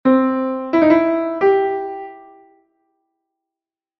No caso de que a nota de adorno esté alterada, colócase encima ou debaixo do símbolo segundo se queira alterar a nota superior ou a inferior:
semitrino_desce_alteracion.mp3